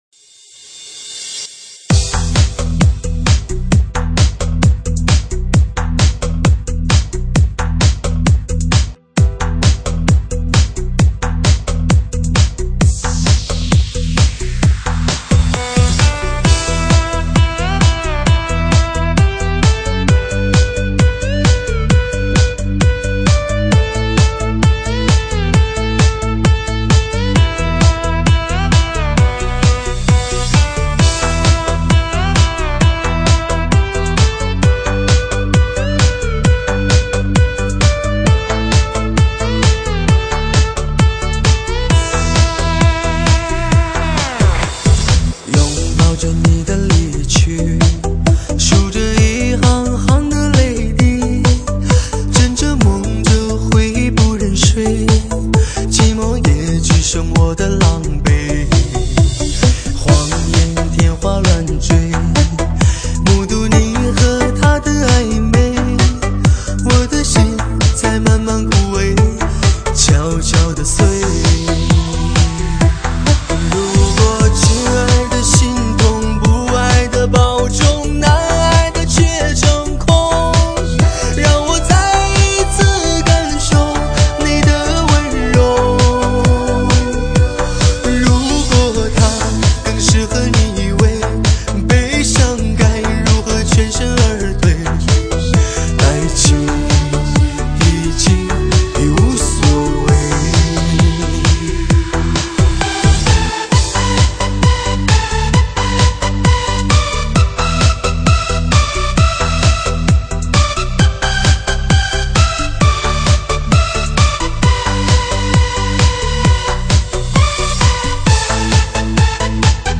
DJ版